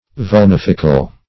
Search Result for " vulnifical" : The Collaborative International Dictionary of English v.0.48: Vulnific \Vul*nif"ic\, Vulnifical \Vul*nif"ic*al\, a. [L. vulnificus; vulnus a wound + facere to make.] Causing wounds; inflicting wounds; wounding.
vulnifical.mp3